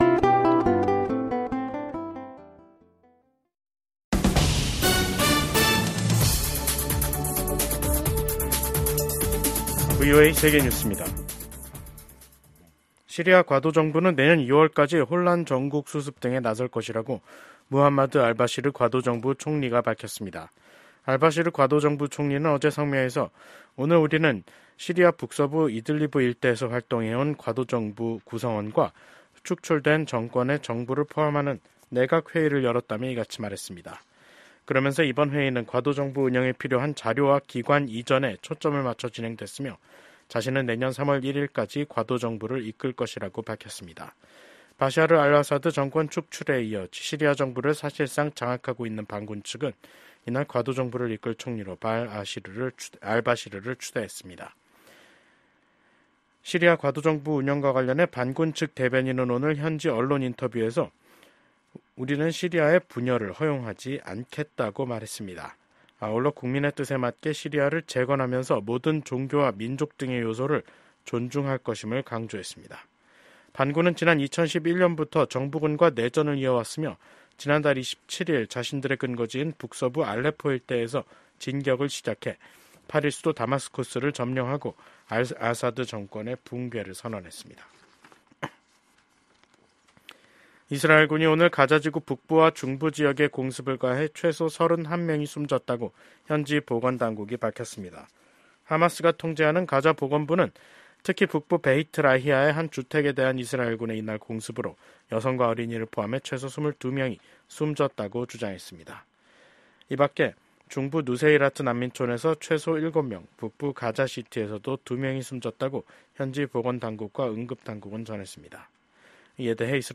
VOA 한국어 간판 뉴스 프로그램 '뉴스 투데이', 2024년 12월 11일 2부 방송입니다. 12.3 비상계엄 사태를 수사하고 있는 한국 사법당국은 사건을 주도한 혐의를 받고 있는 김용현 전 국방부 장관을 구속했습니다. 한국의 비상계엄 사태 이후 한국 민주주의가 더욱 강해졌다고 미국 인권 전문가들이 평가했습니다. 윤석열 대통령이 현 상황에서 효과적으로 한국을 통치할 수 있을지 자문해야 한다고 미국 민주당 소속 매릴린 스트릭랜드 하원의원이 밝혔습니다.